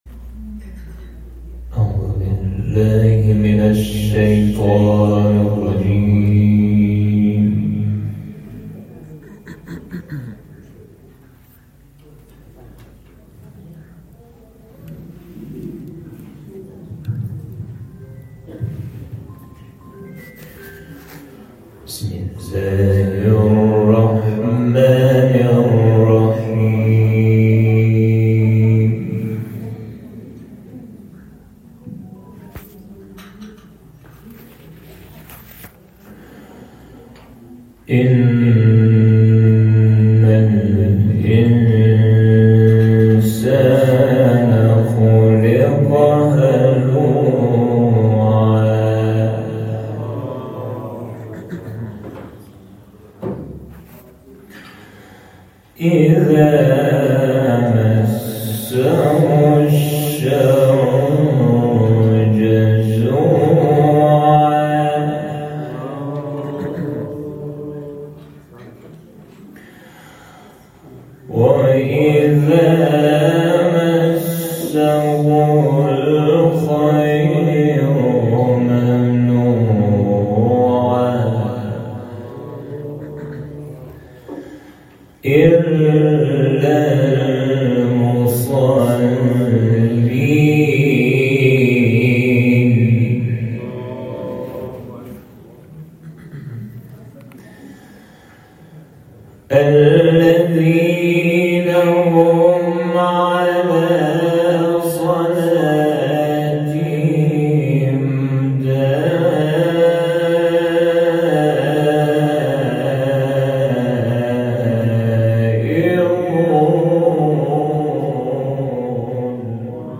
تلاوت 40-19 سوره معارج